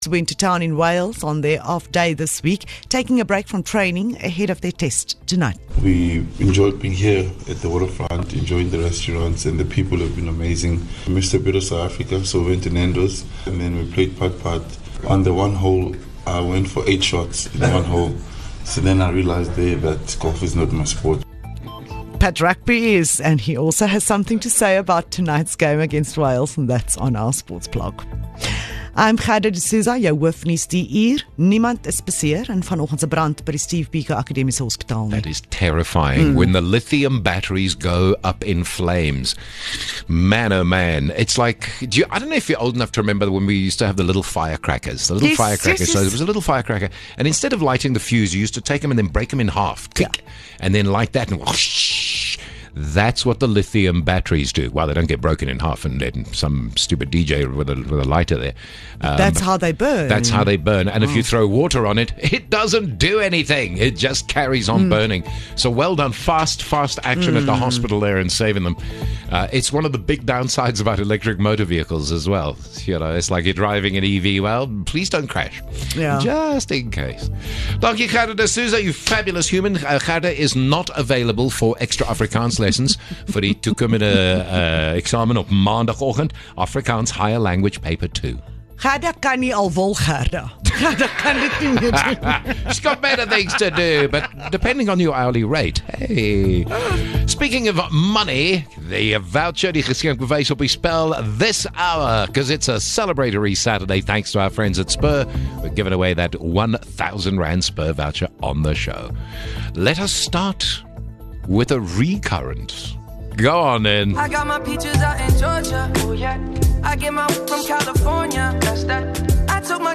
The Jacaranda FM News team is based in Gauteng – but covers local and international news of the day, providing the latest developments online and on-air. News bulletins run from 5am to 7pm weekdays, and from 7am to 6pm on weekends and public holidays.